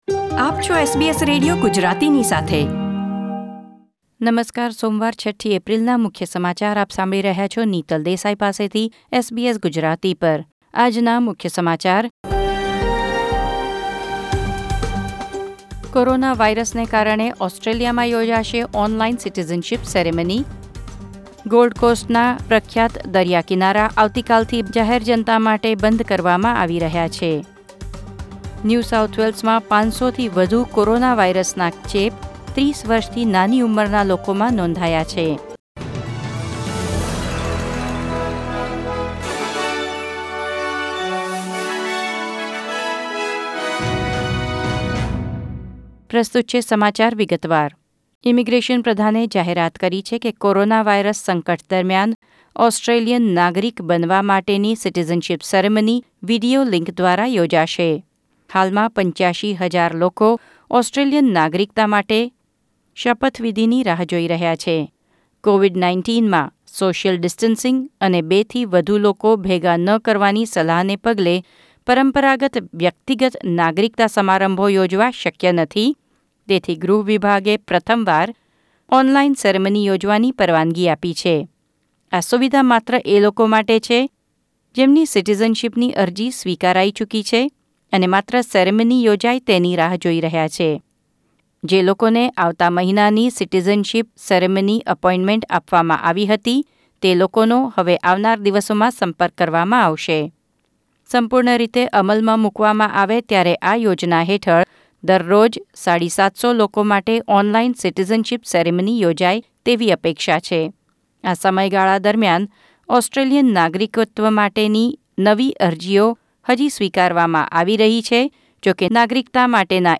૬ એપ્રિલ ૨૦૨૦ના મુખ્ય સમાચાર